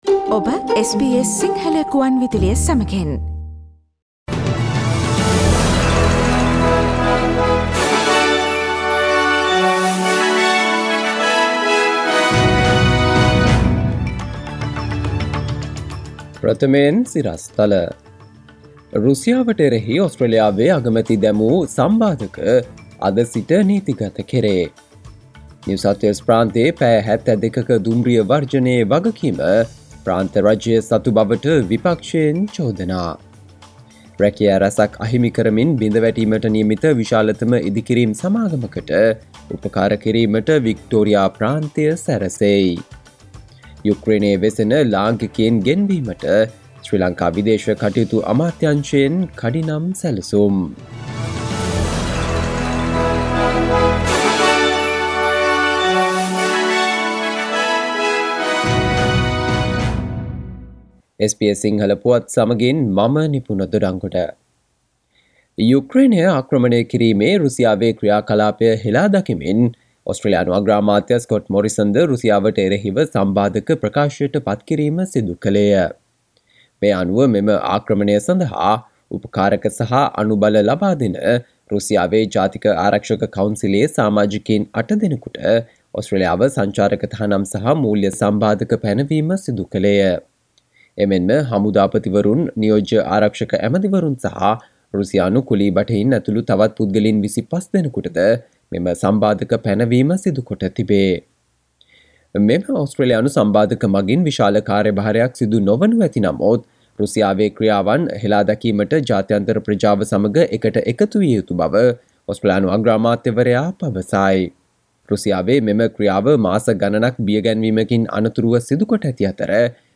සවන්දෙන්න 2022 පෙබරවාරි 25 වන සිකුරාදා SBS සිංහල ගුවන්විදුලියේ ප්‍රවෘත්ති ප්‍රකාශයට...